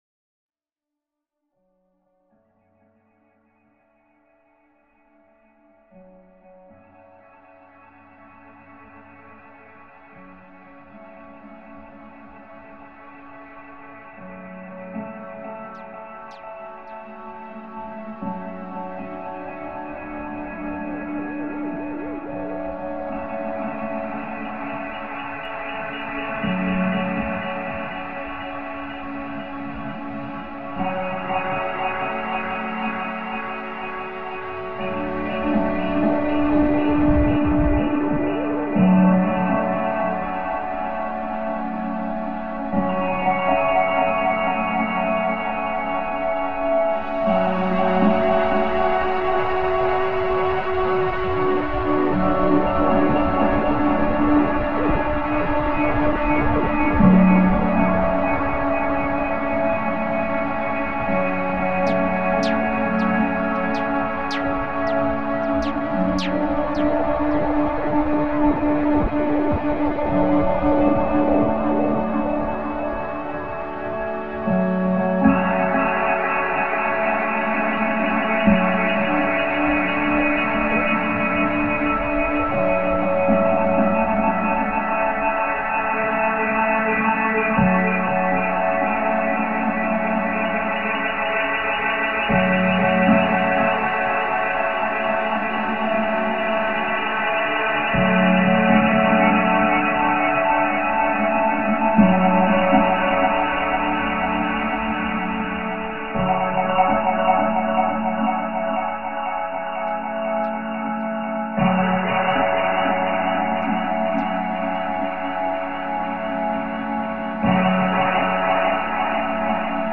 Genre: Ambient/Drone/Field Recordings.